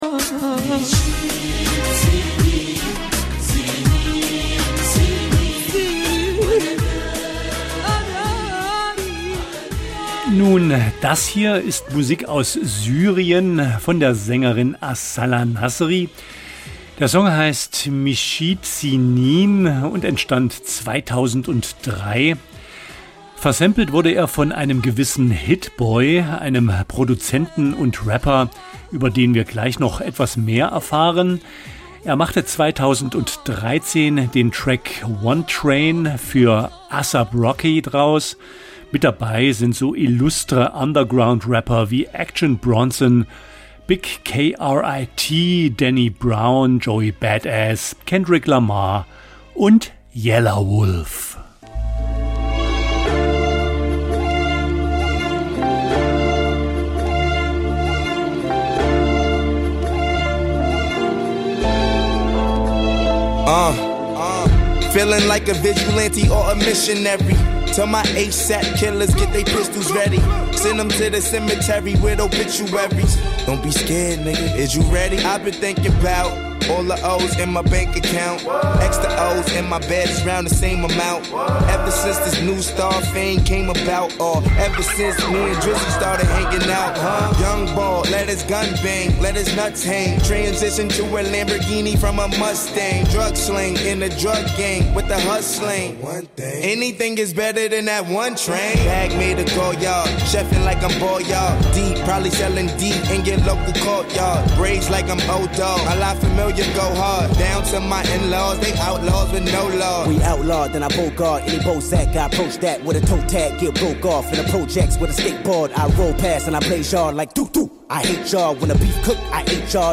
Hinter WHAT ABOUT BREAKS? verbergen sich unter dem alles �berspannenden Dach der Hip Hop Kultur neben den vorher genannten Stilrichtungen auch BLUES, LATIN, ELECTRO, REGGAE und POP mit jeder Menge Hintergrundinfos zur Musik und den K�nstlern.